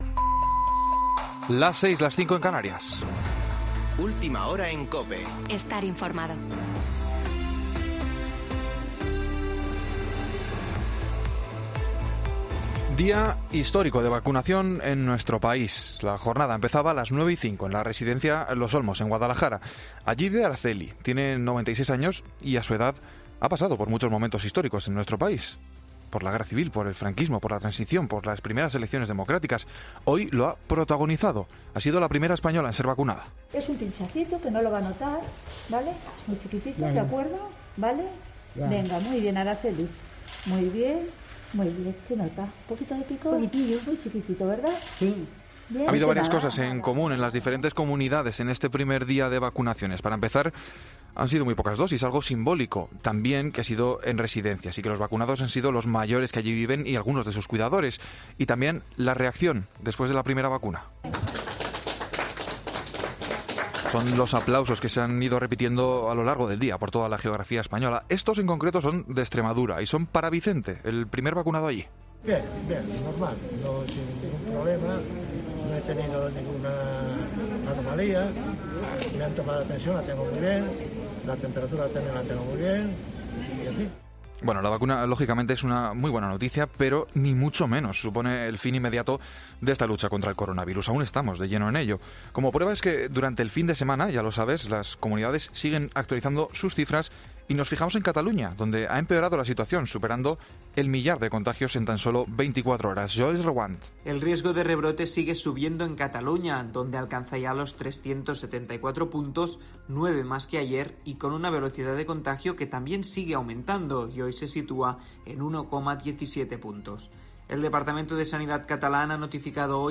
AUDIO: Boletín de noticias de COPE del 27 de diciembre de 2020 a las 18.00 horas